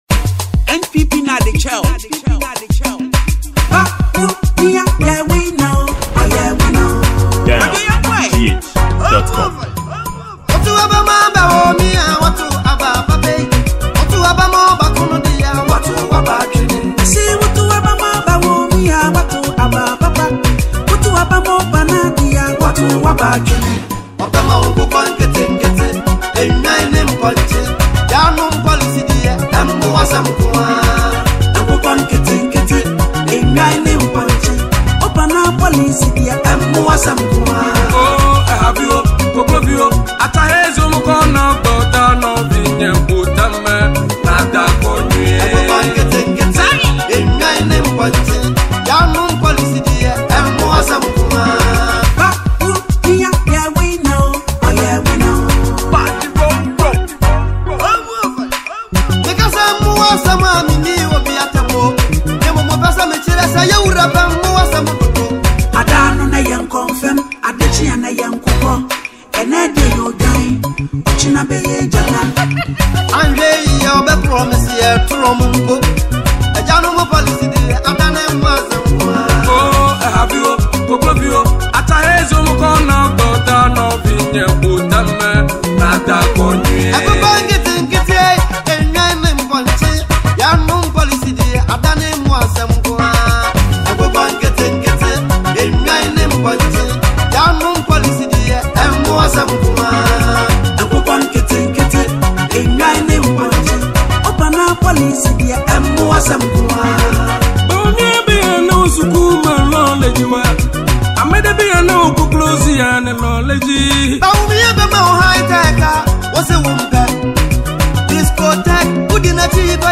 Ghana Music
a political song